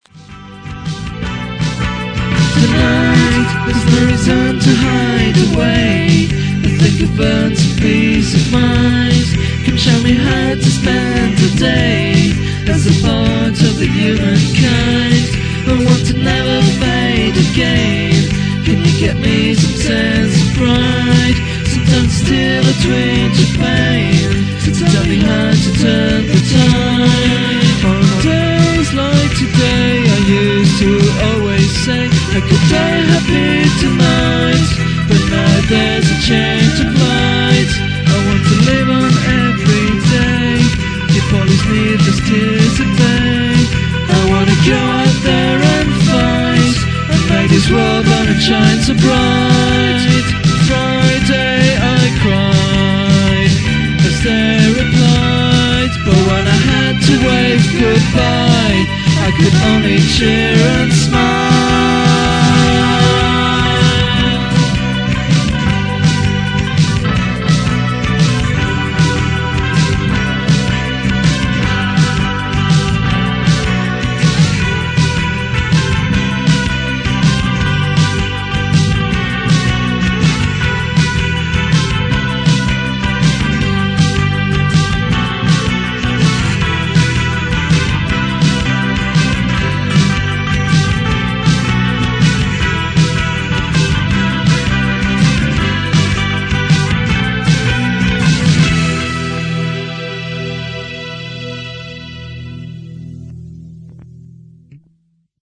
OK, let's be fair, I think the singing is atrocious, the music mostly rubbish, and the lyrics cringeworthy.